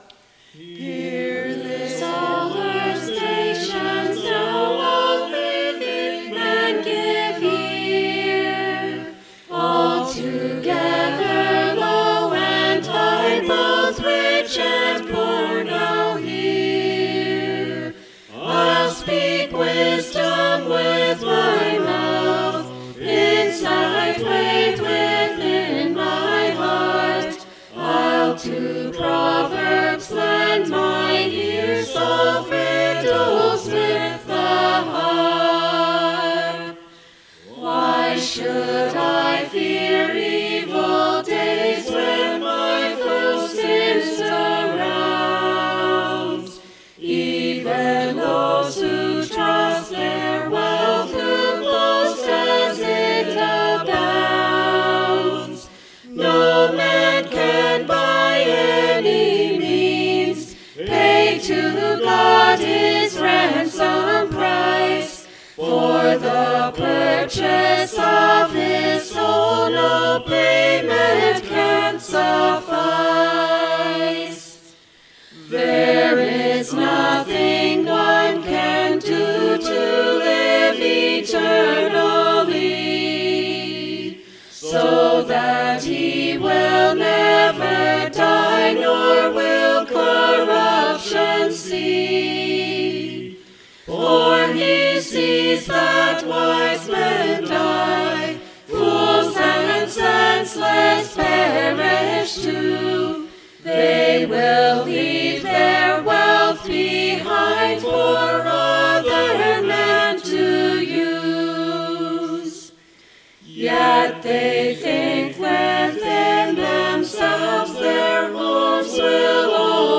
Psalms Recorded by EPC